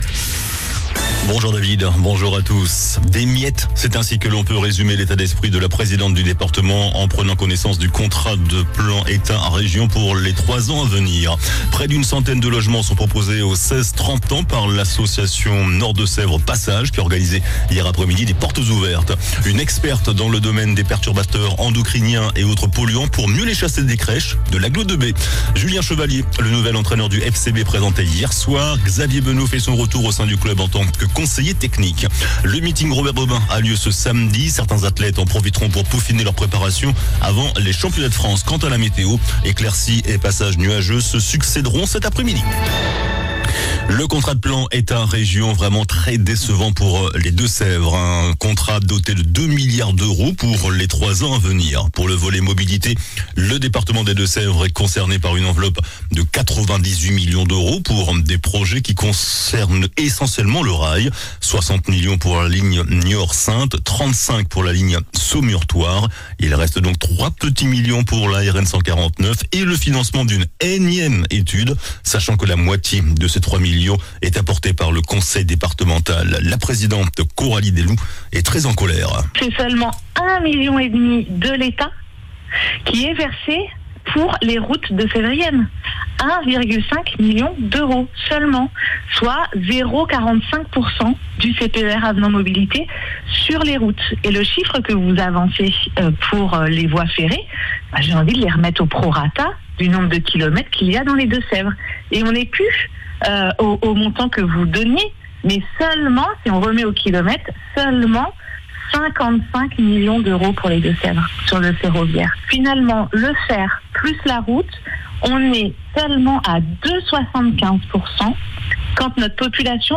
JOURNAL DU JEUDI 20 JUIN ( MIDI )